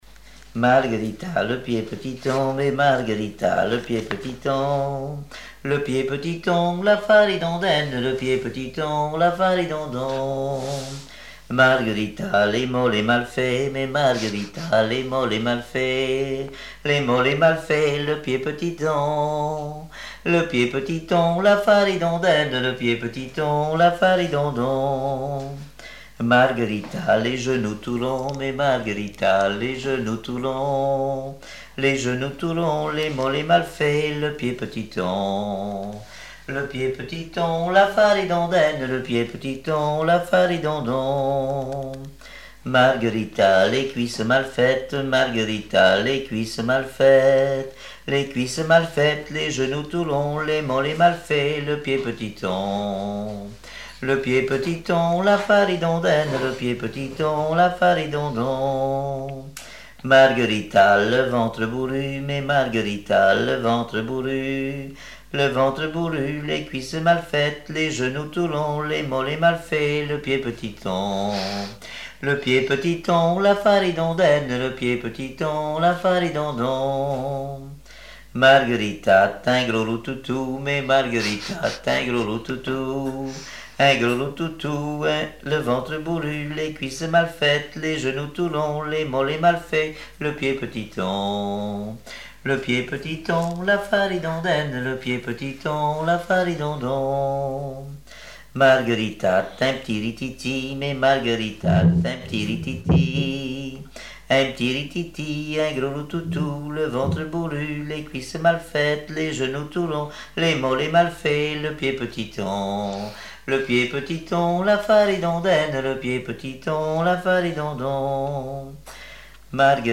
Mémoires et Patrimoines vivants - RaddO est une base de données d'archives iconographiques et sonores.
Genre énumérative
Répertoire de chansons traditionnelles et populaires
Pièce musicale inédite